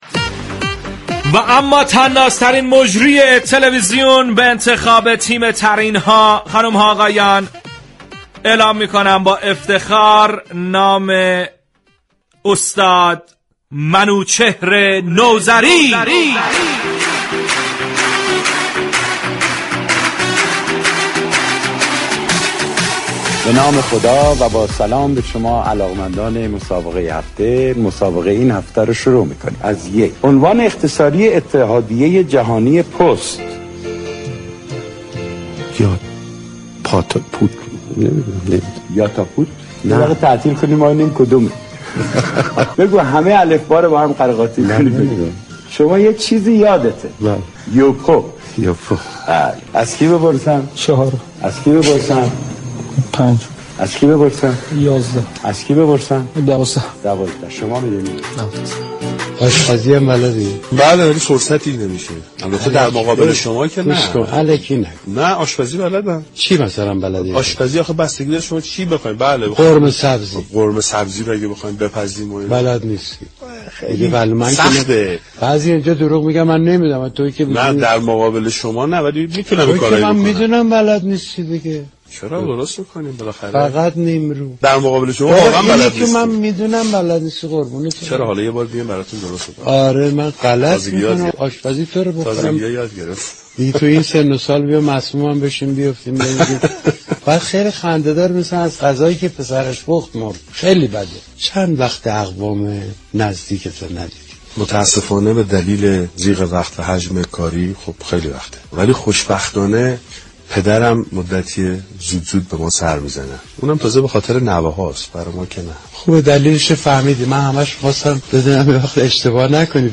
این برنامه در فضایی شاد و پرانرژی تقدیم مخاطبان می شود.
در این برنامه با پخش قسمت های از اجرای این مجریان در فضایی شاد و با هیجان نتایج آرای مخاطبان در فضای مجازی و كارشناسی تیم برنامه به صورت زیر اعلام شد.